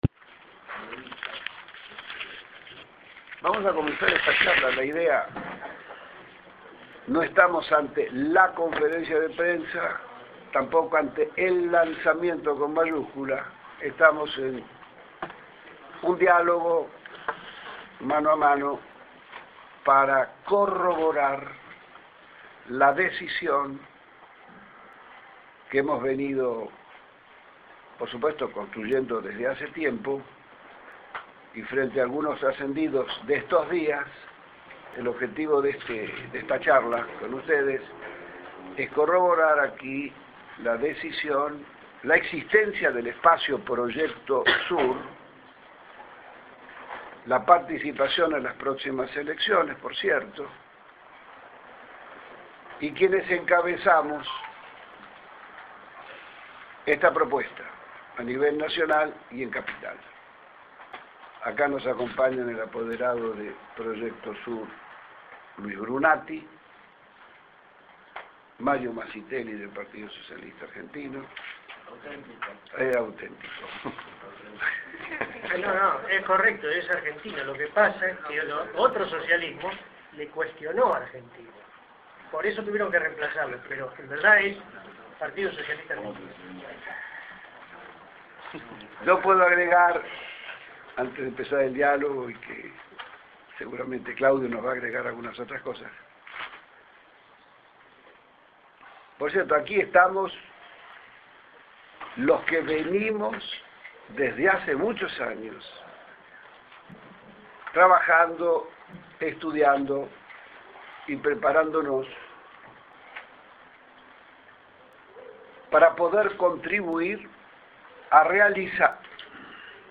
CONFERENCIA PRENSA (MP3 - 3.6 MB)
lanzamiento_PROYECTO_SUR_PARTE1.mp3